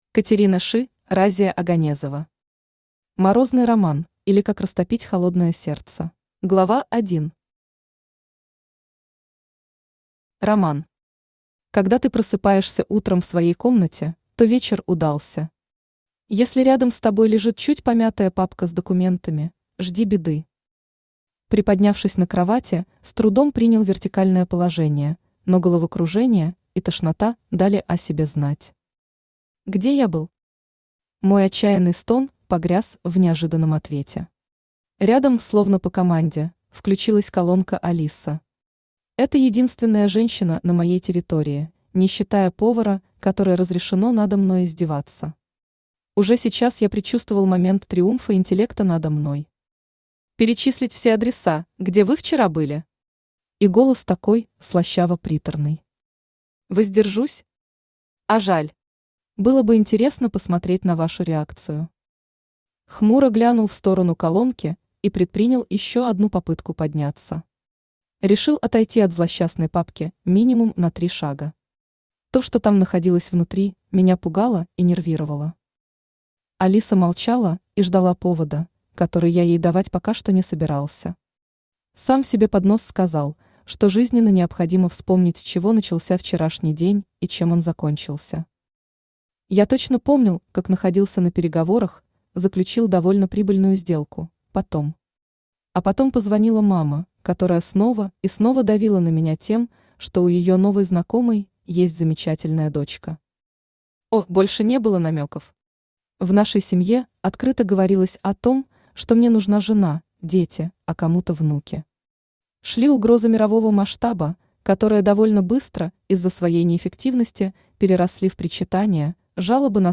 Аудиокнига Морозный роман, или Как растопить холодное сердце | Библиотека аудиокниг
Aудиокнига Морозный роман, или Как растопить холодное сердце Автор Разия Оганезова Читает аудиокнигу Авточтец ЛитРес.